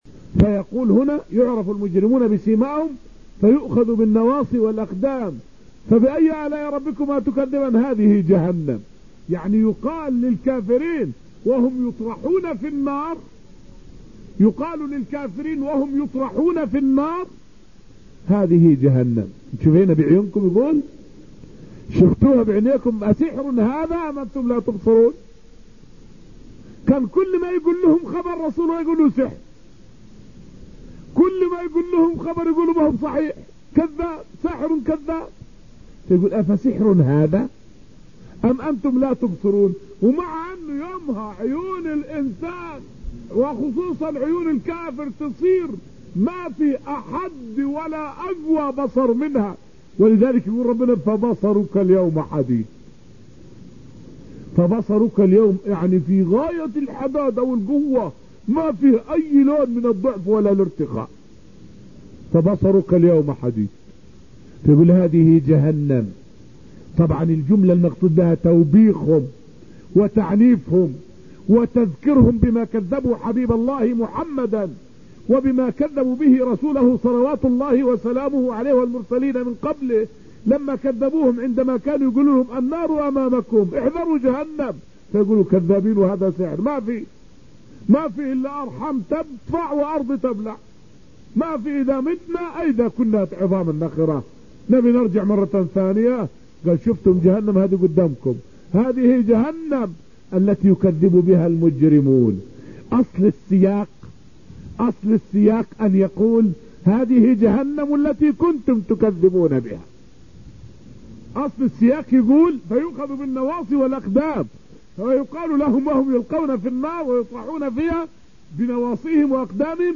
فائدة من الدرس العاشر من دروس تفسير سورة الرحمن والتي ألقيت في المسجد النبوي الشريف حول معنى {هذه جهنم التي يكذب بها المجرمون}.